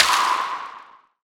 Icegun Cube Breaks Sound Effect
Download a high-quality icegun cube breaks sound effect.
icegun-cube-breaks.mp3